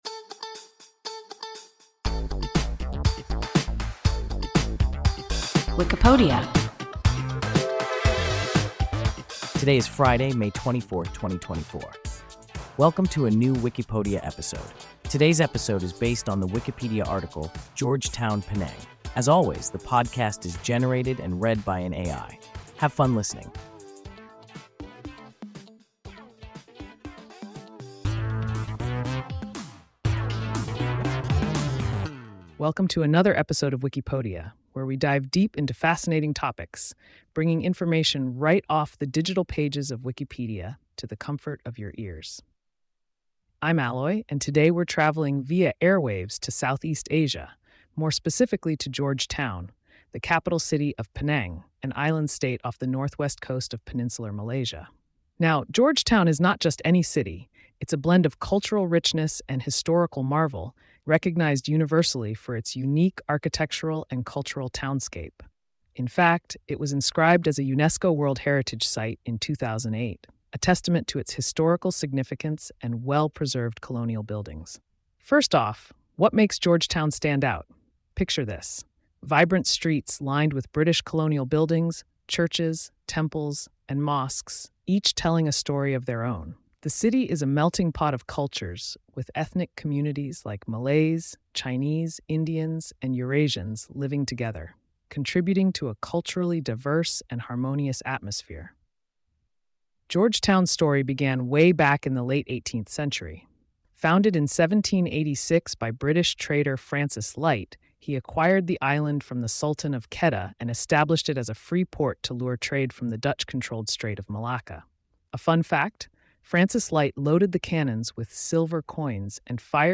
George Town, Penang – WIKIPODIA – ein KI Podcast